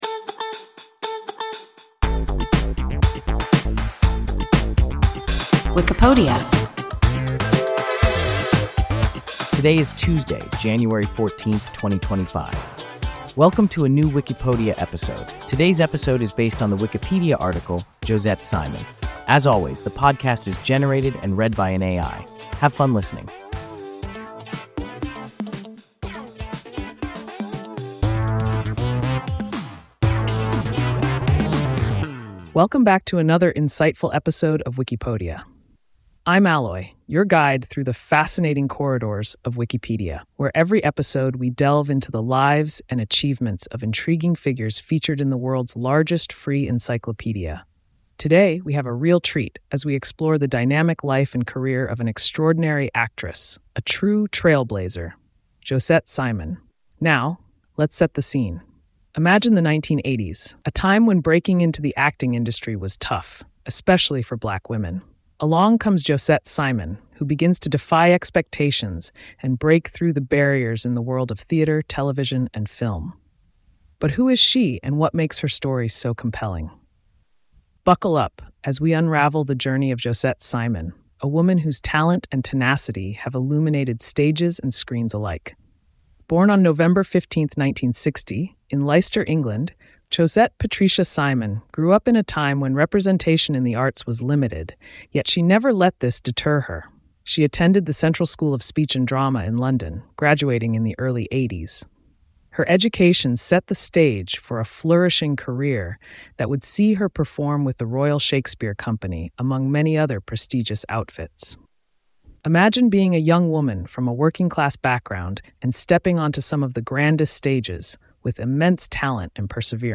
Josette Simon – WIKIPODIA – ein KI Podcast